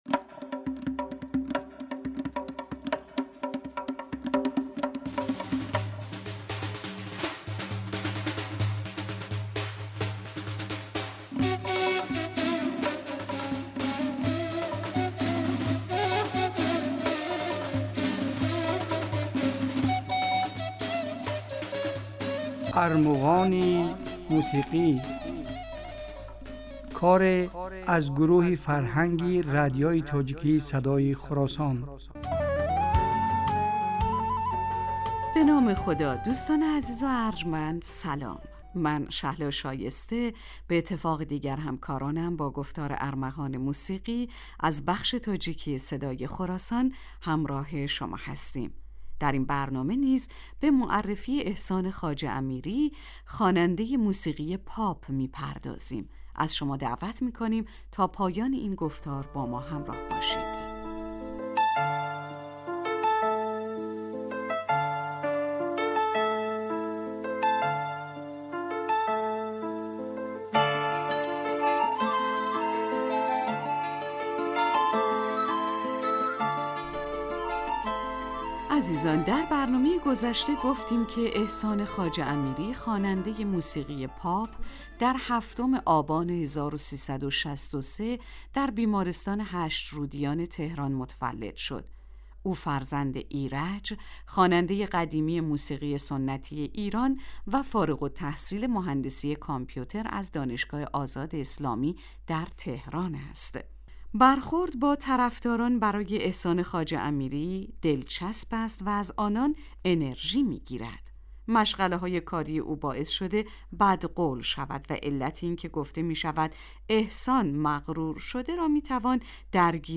Дар ин барномаҳо кӯшиш мекунем, ки беҳтарин ва зеботарин мусиқии тоҷикӣ ва...